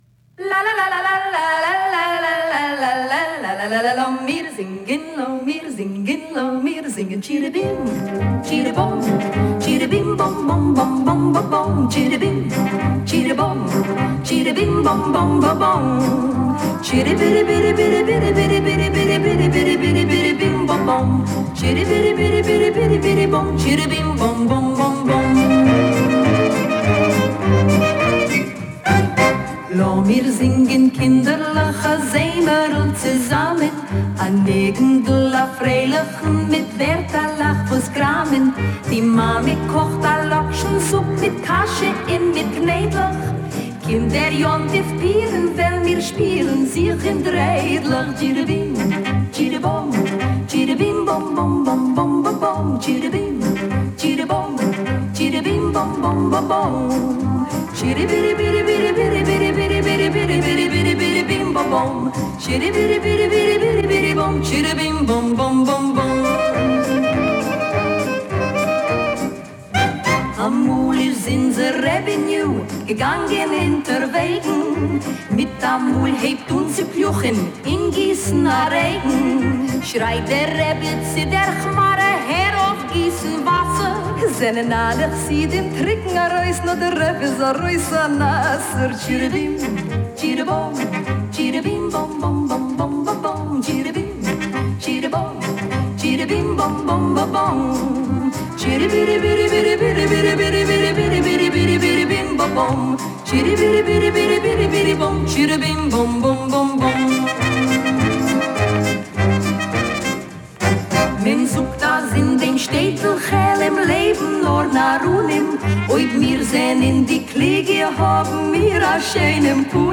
вокальном дуэте